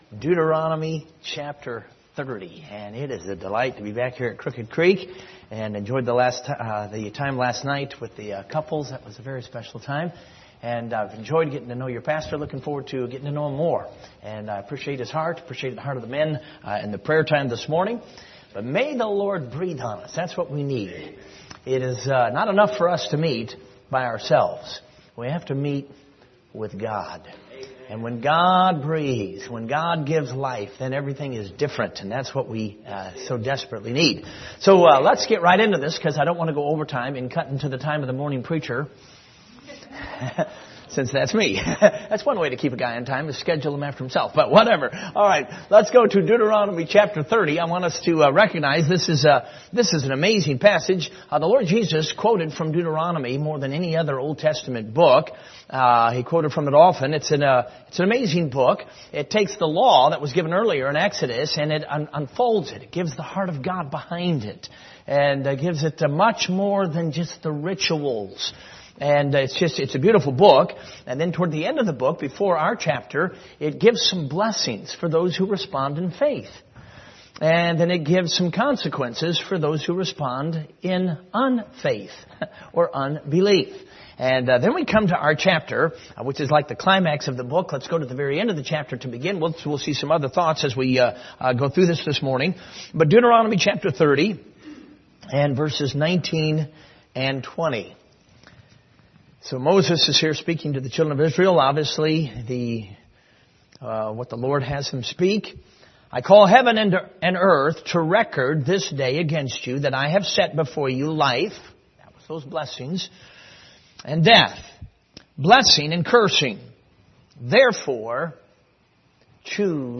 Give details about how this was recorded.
Deuteronomy 30:19-20 Service Type: Sunday Morning View the video on Facebook Topics